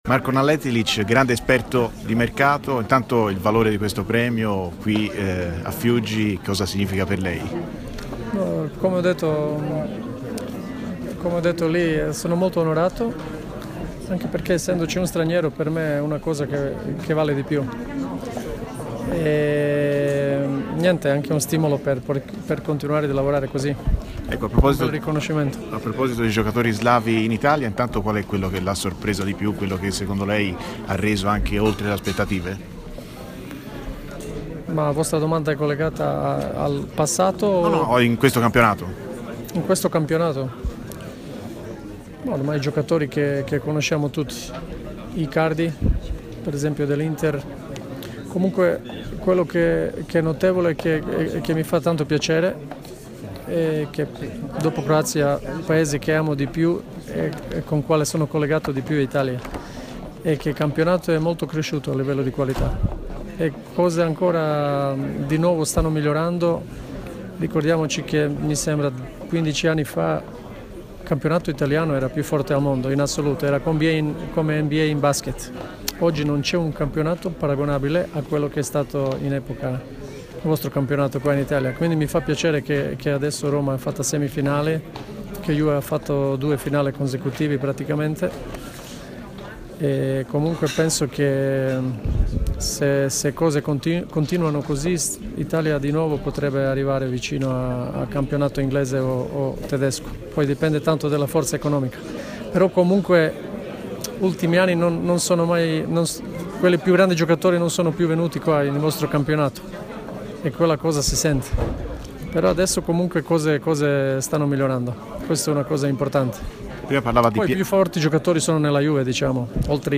al Premio Maestrelli a Fiuggi